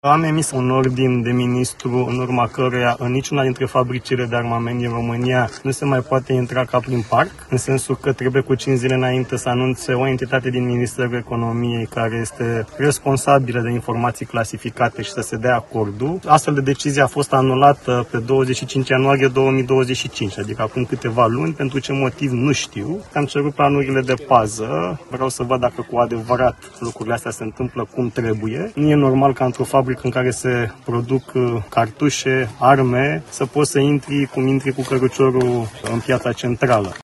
Ministrul Radu Miruță a amintit de ordinul pe care l-a semnat și care prevede că nimeni nu mai poate intra în fabricile de armament și muniție fără aprobarea Ministerului Economiei.